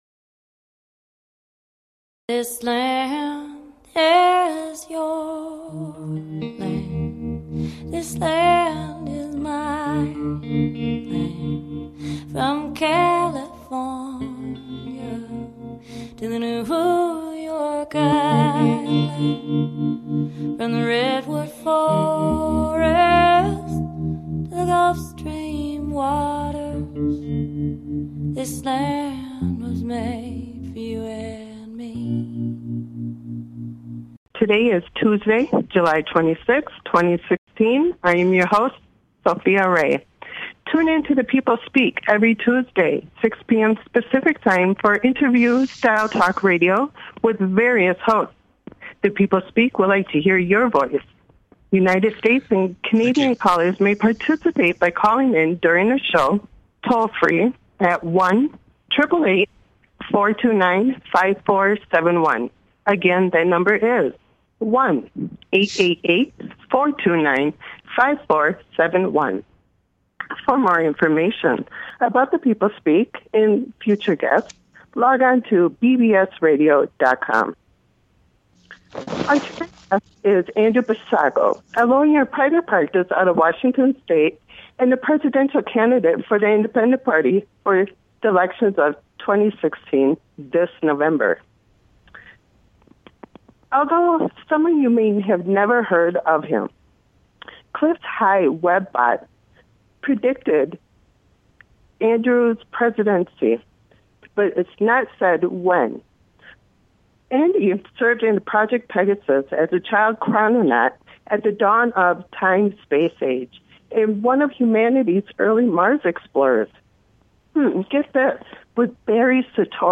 The People Speak has evolved over the years with many great guests who have been interviewed by some very fine hosts.